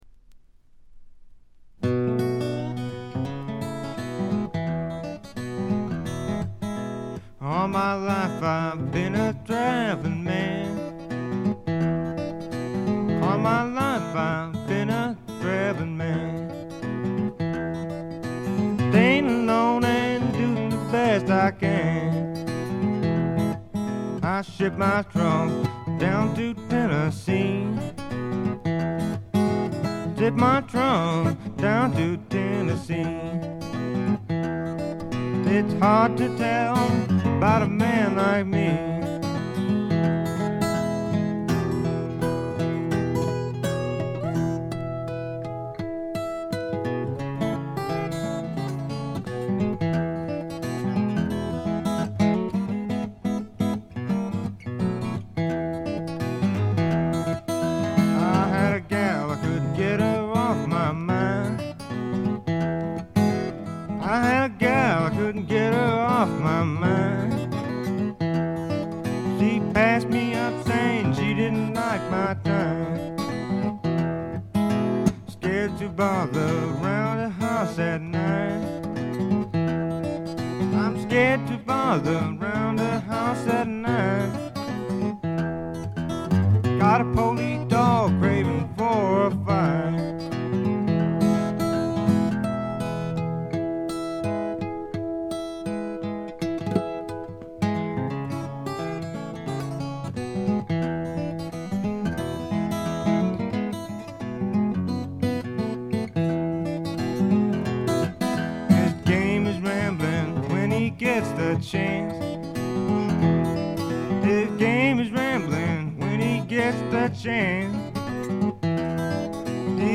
ほとんどノイズ感無し。
試聴曲は現品からの取り込み音源です。
Guitar, Mandolin, Bass